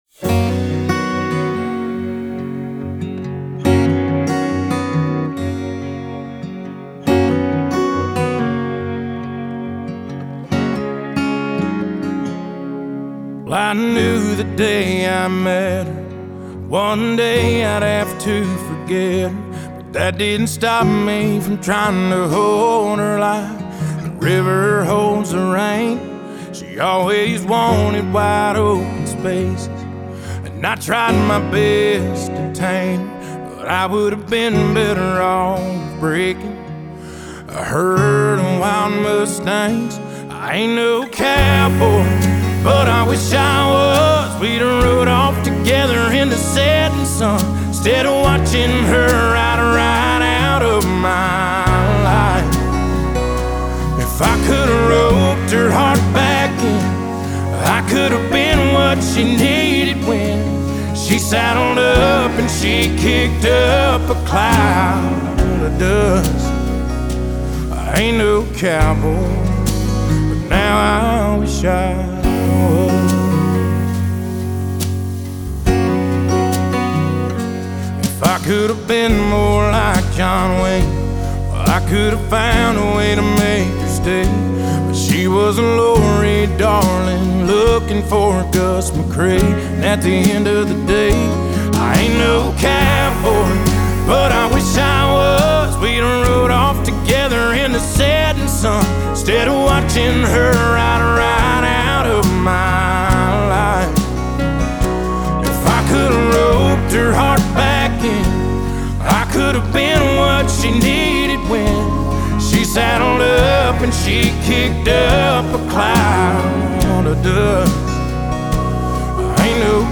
Трек размещён в разделе Зарубежная музыка / Кантри.